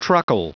Prononciation du mot truckle en anglais (fichier audio)
Prononciation du mot : truckle